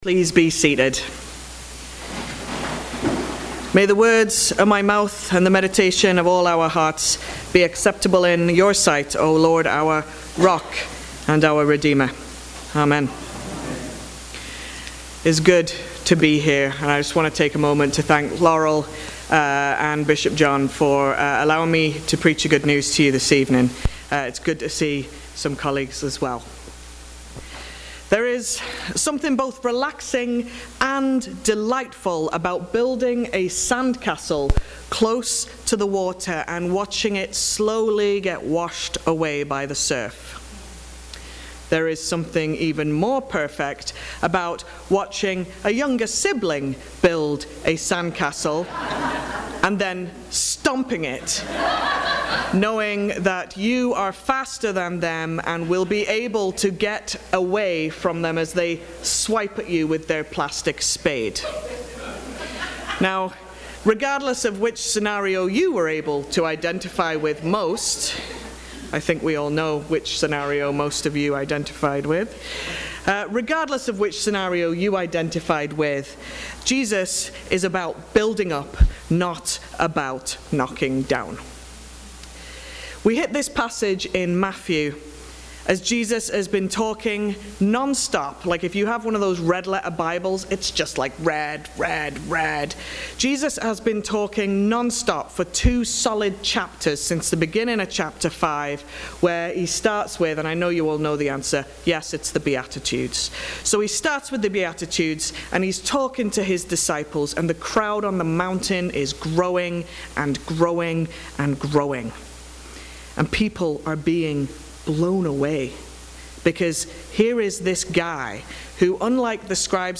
Sermons | St. David and St. Paul Anglican Church
Service of Induction